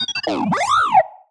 happy6.wav